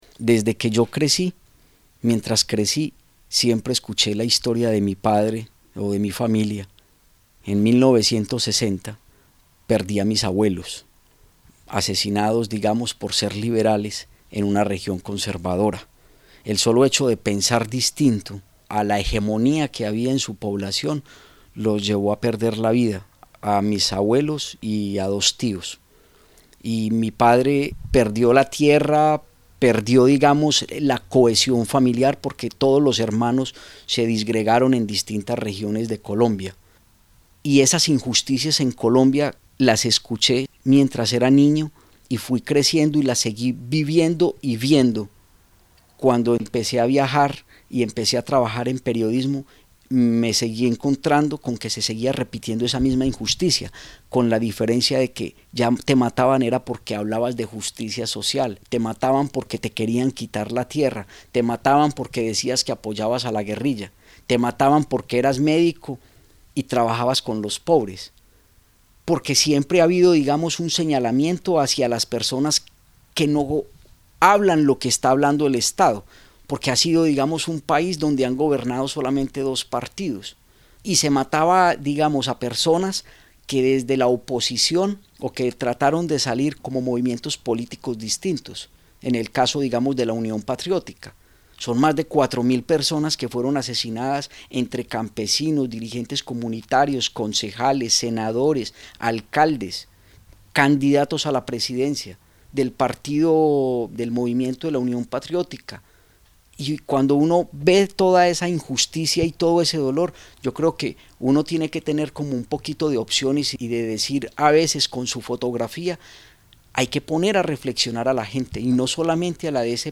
Desde muy joven, en el seno de su familia, Jesús Abad Colorado supo de la injusticia y la impunidad en Colombia, del riesgo de pensar diferente a los que detentan el poder. El fotógrafo y periodista habla a swissinfo de sus motivaciones para denunciar «el dolor de la guerra».